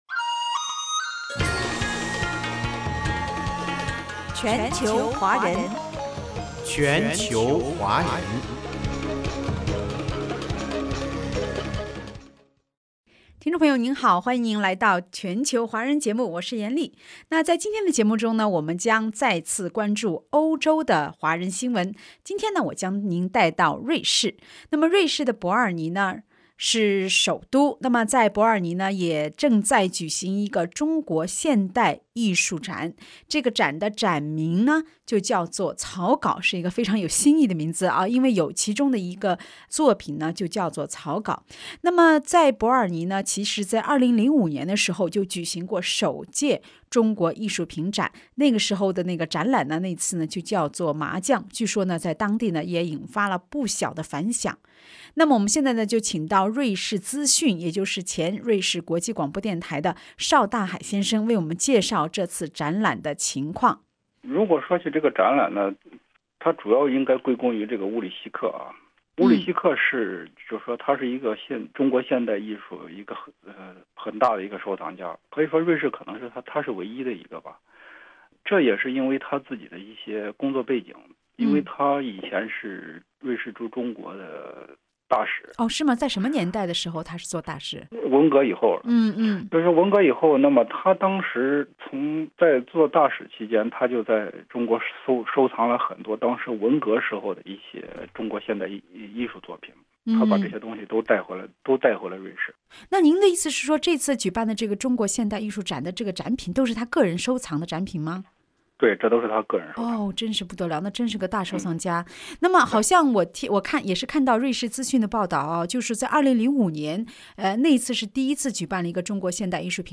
澳洲广播电台《全球华人》栏目关于《草稿》采访瑞士资讯